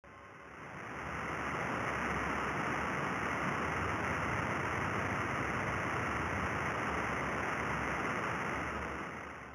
Typically the frequency of scattered light is below 30 Hz and the time period for the humps to repeat can be between 0.1 second and several seconds.